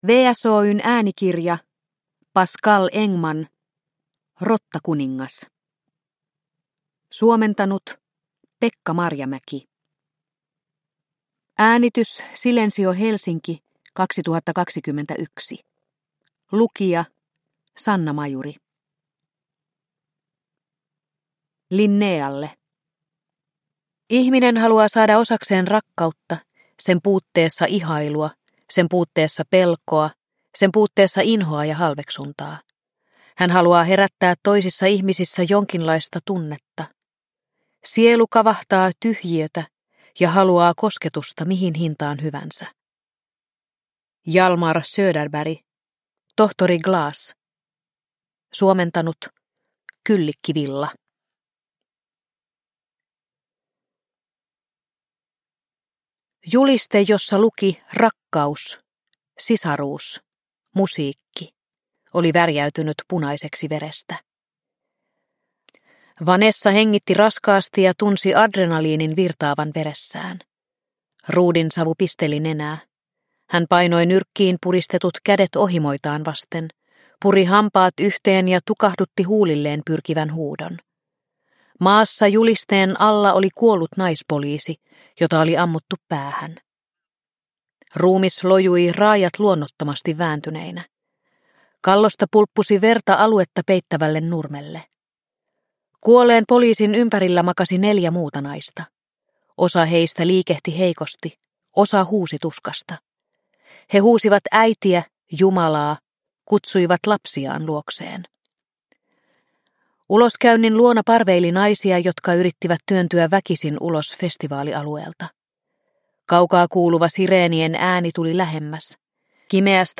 Rottakuningas – Ljudbok – Laddas ner